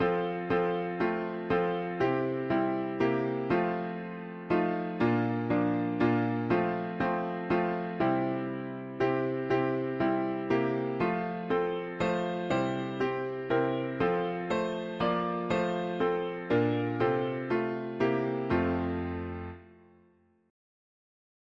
We share each other’s woes, each oth… english christian 4part chords